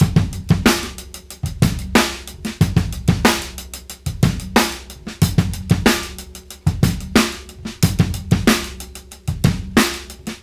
• 92 Bpm Drum Loop Sample G Key.wav
Free breakbeat - kick tuned to the G note. Loudest frequency: 1979Hz
92-bpm-drum-loop-sample-g-key-2Gz.wav